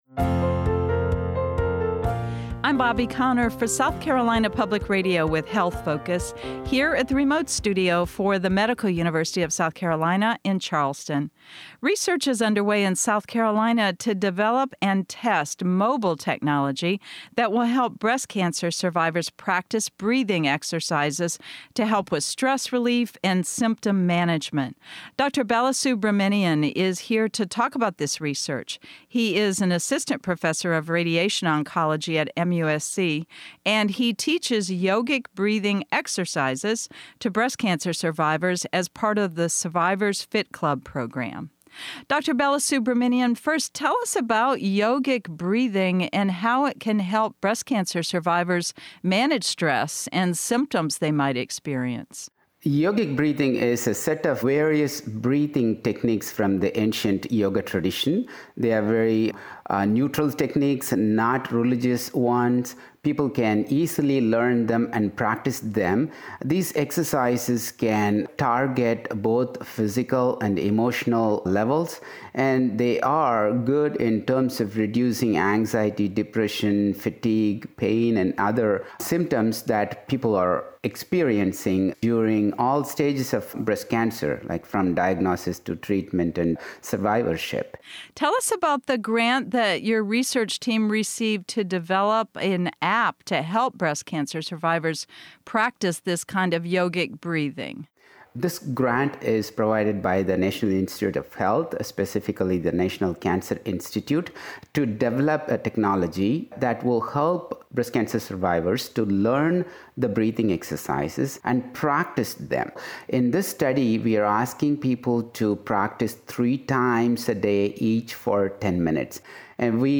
The science of yogic breathing. Interview at Yoga and Integrative Medicine Institute, Australia.